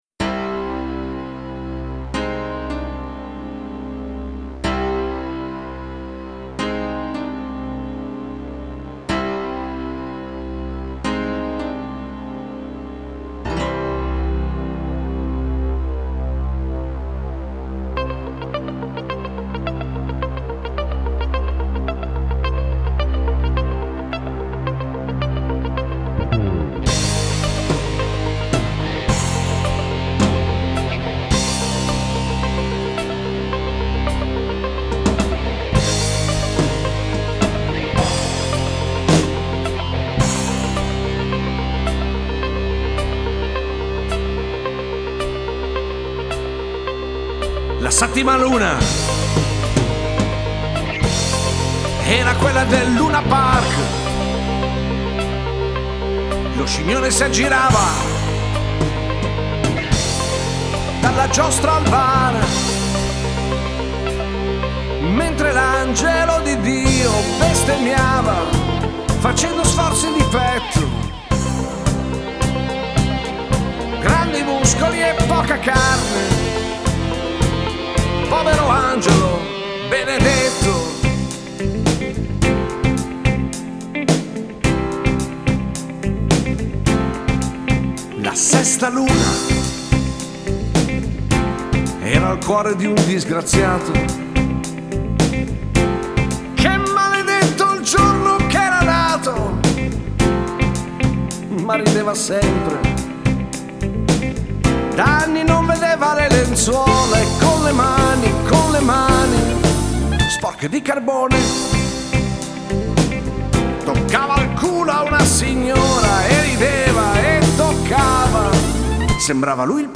sola musica italiana 80 e 90 | Cover band di Genova